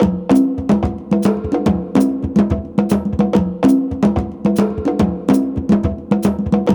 CONGBEAT10-L.wav